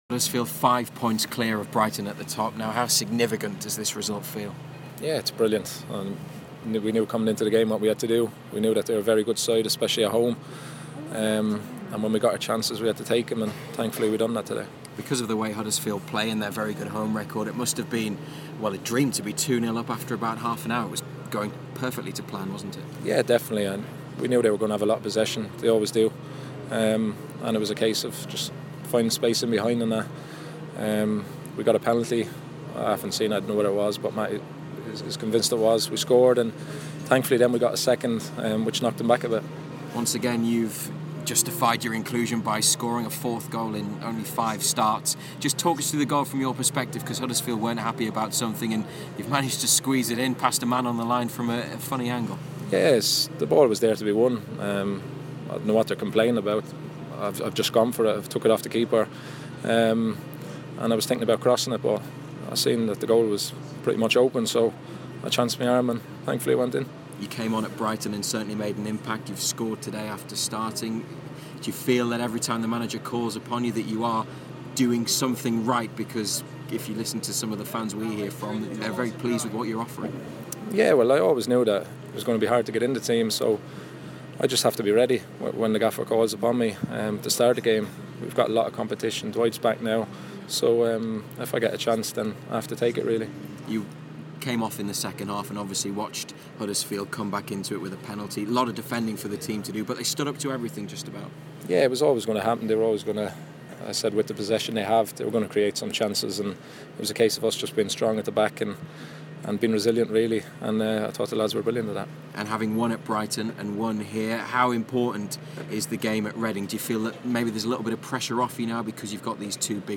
Daryl Murphy spoke to BBC Newcastle after scoring in the Magpies' 3-1 win at Huddersfield.